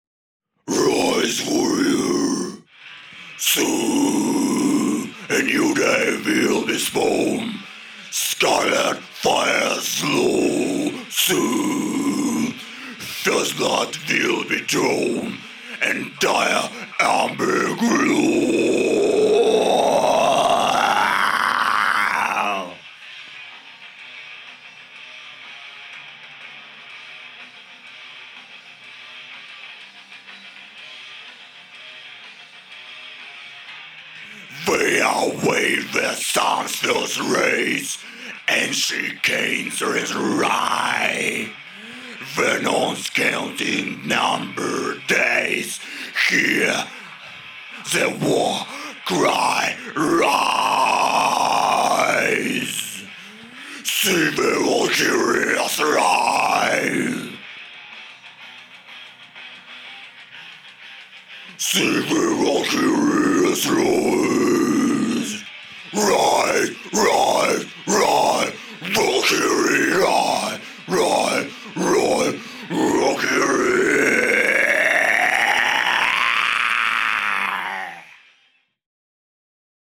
Вокал. Демо